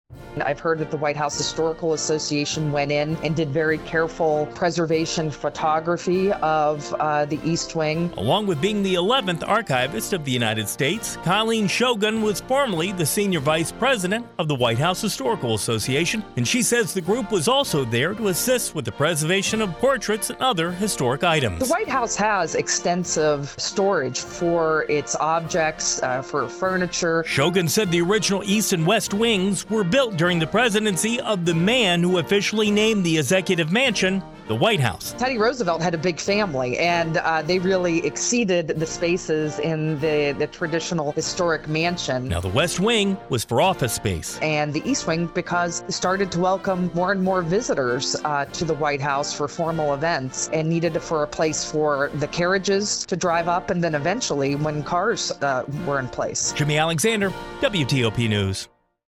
Colleen Shogun, the 11th Archivist of the United States, shines a light on the history of the East Wing.
1-east-wing-archivist.wav